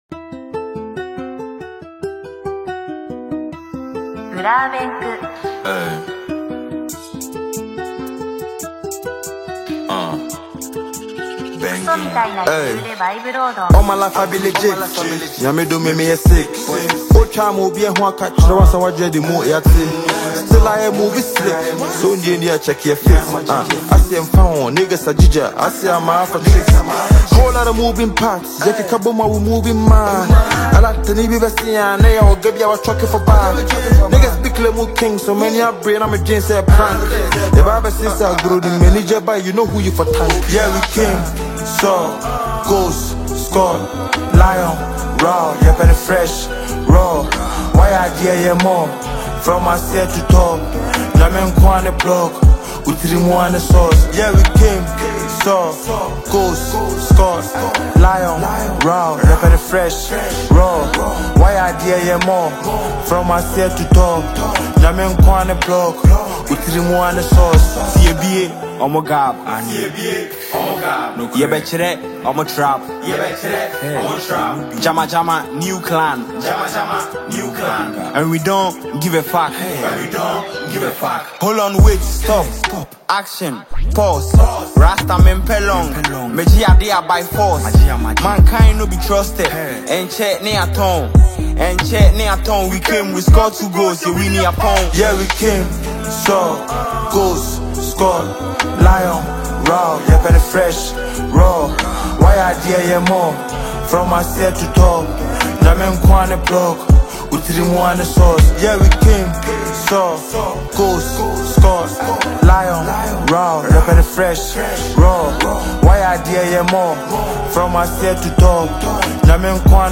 Ghana Music
Hip-Hop
a blend of airy synth pads and rhythmic percussion